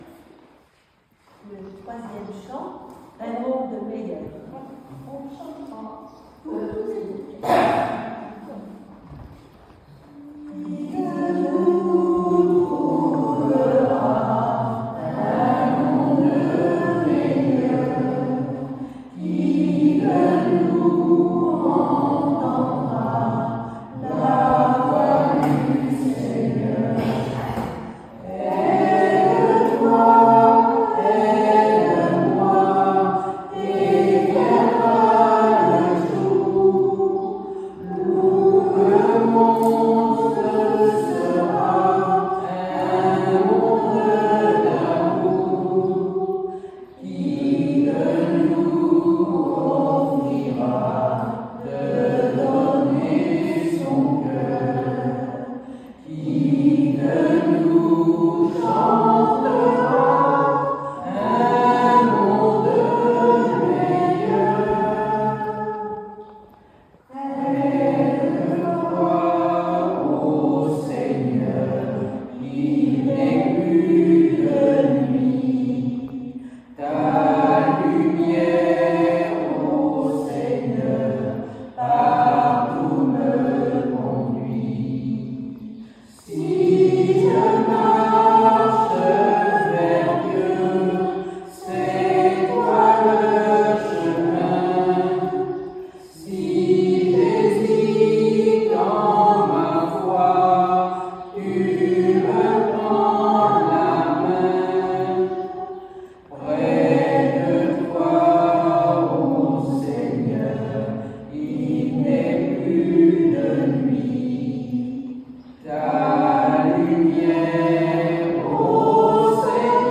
fond sonore = chant final chanté lors de l'AD du 13/07/2025)